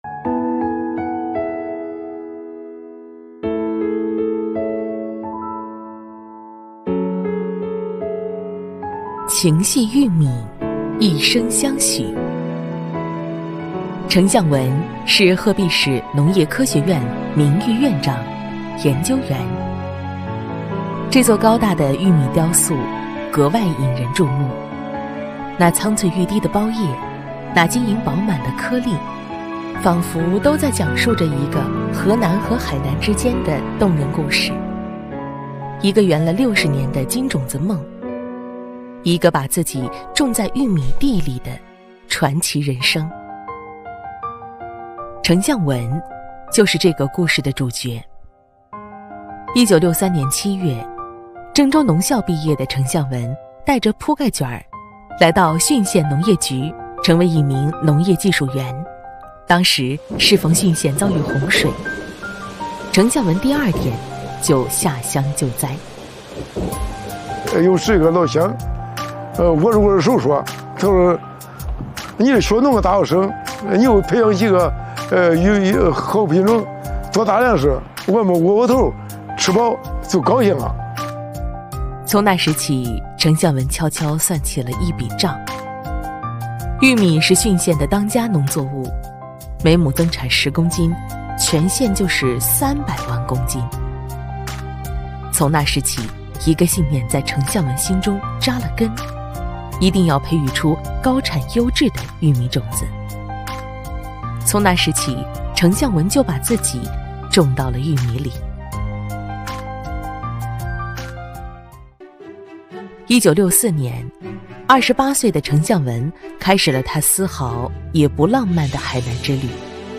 - 主题诵读作品 -